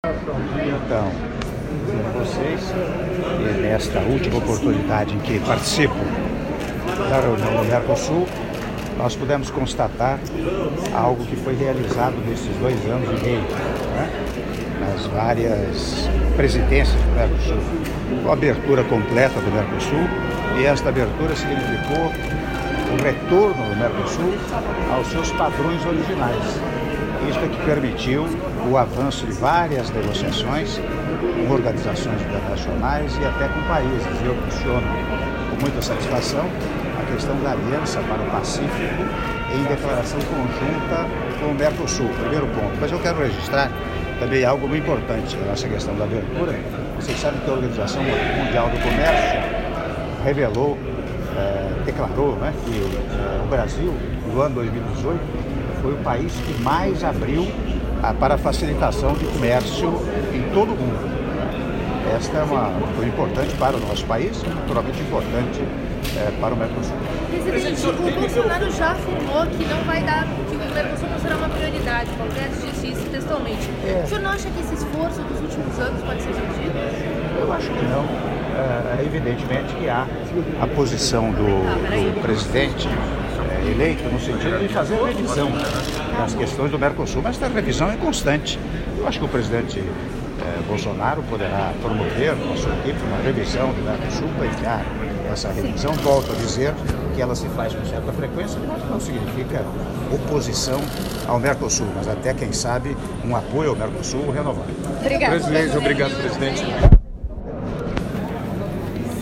Áudio da Entrevista coletiva concedida pelo Presidente da República, Michel Temer, após Sessão Plenária da Cúpula dos Presidentes dos Estados Partes do Mercosul, Estados Associados e Convidados Especiais - Montevidéu/Uruguai (01min49s)